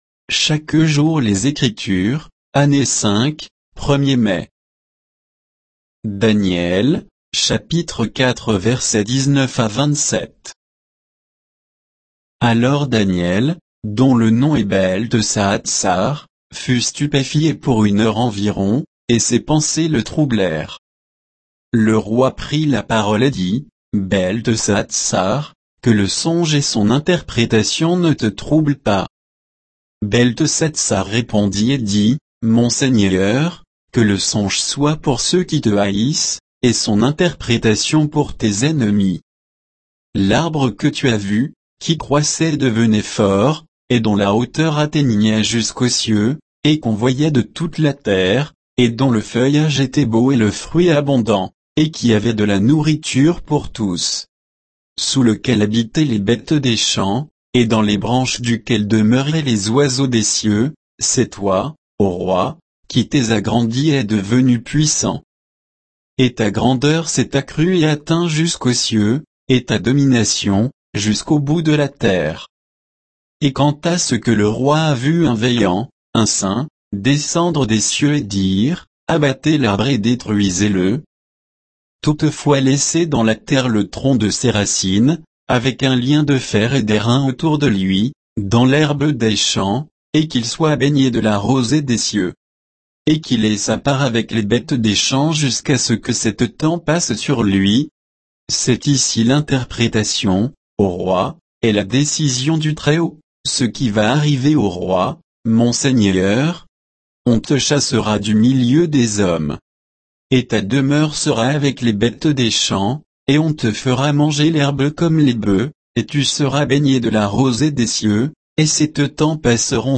Méditation quoditienne de Chaque jour les Écritures sur Daniel 4